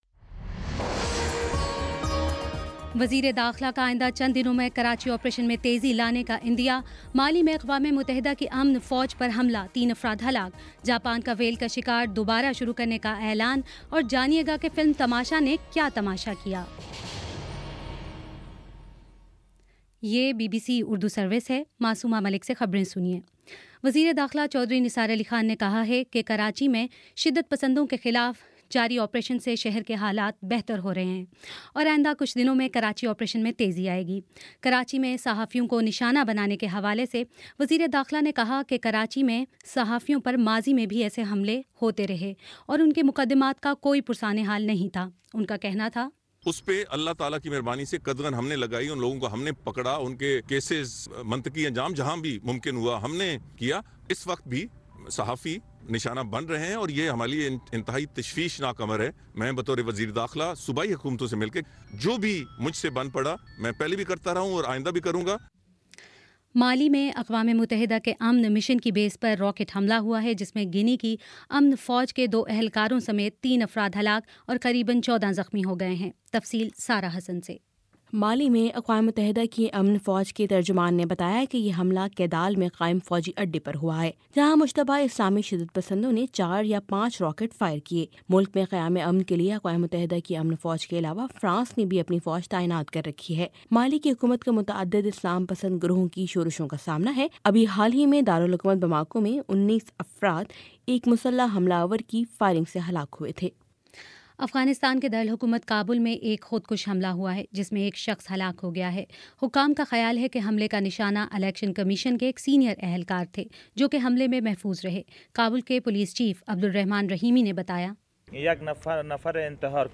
نومبر 28 : شام چھ بجے کا نیوز بُلیٹن